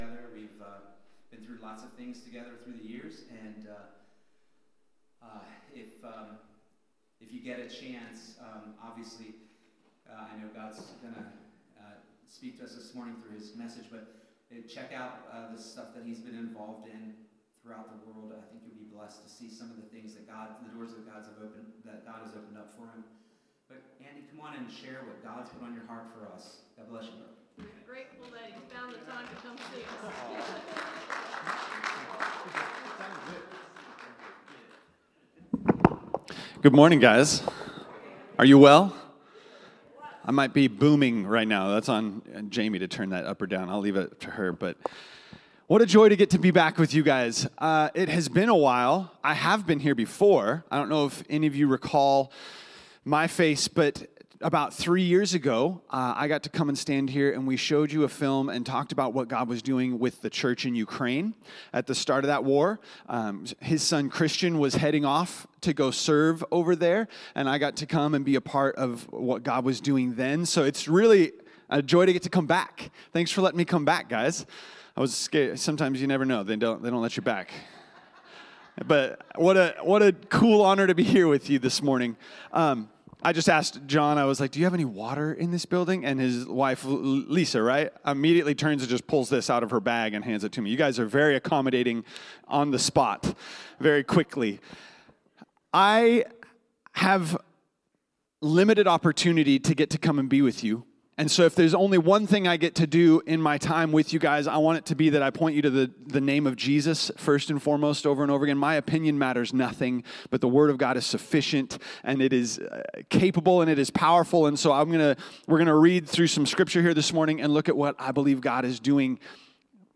Sunday Evening Worship December 28, 2025.
The Pulpit Ministry of New Covenant Church, Lewes